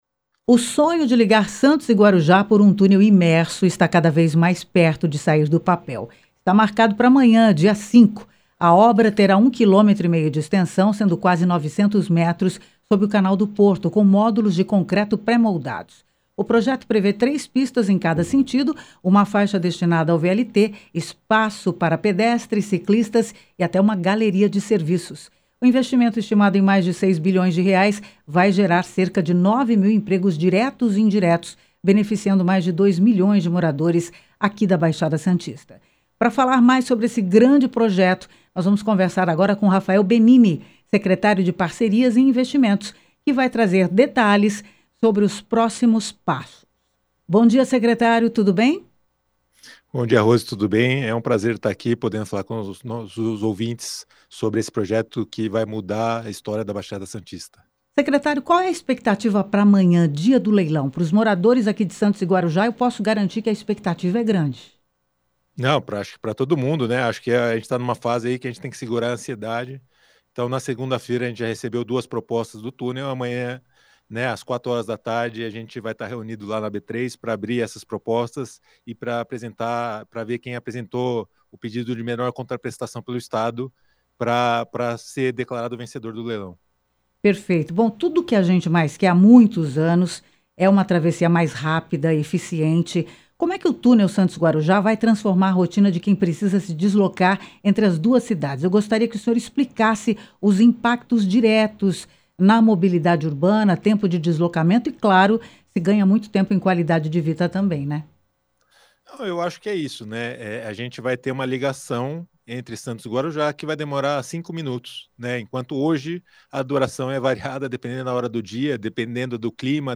Secretário Rafael Benini, fala ao Conexão,sobre o Leilão do Túnel Santos- Guarujá – SAUDADE FM